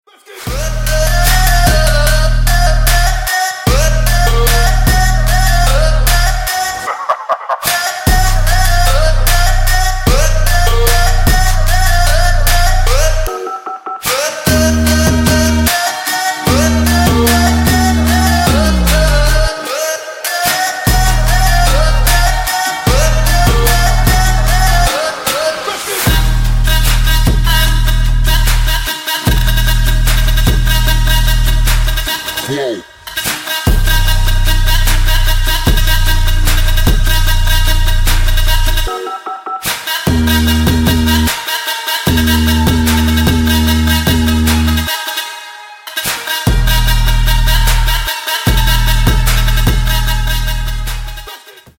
• Качество: 160, Stereo
Trap
Bass
Hardstyle